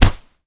kick_soft_jab_impact_08.wav